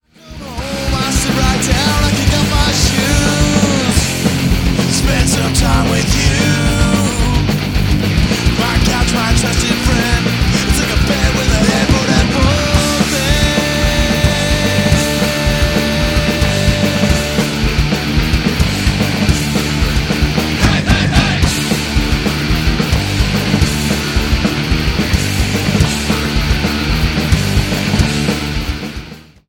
Thirteen in your face punk rock gems.